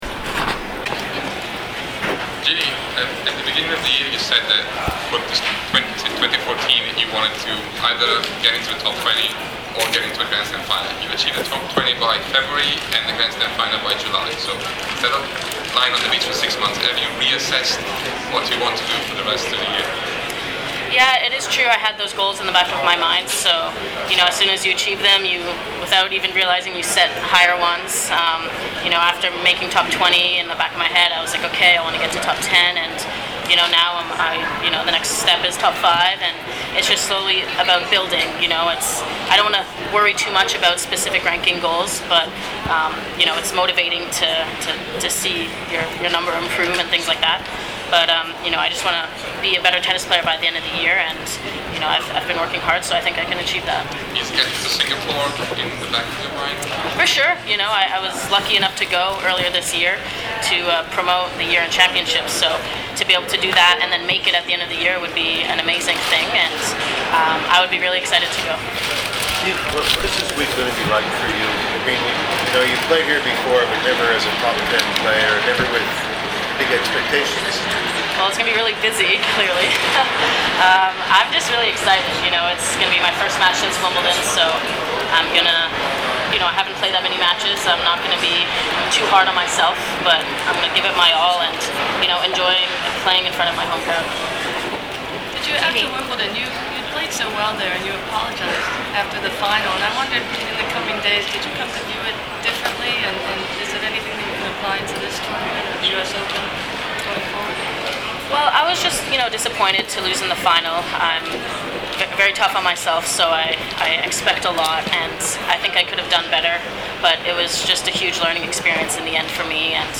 TENNIS – Le interviste pre-torneo di alcune delle protagoniste di questa edizione della Rogers Cup: Sharapova, Bouchard, Azarenka e Jankovic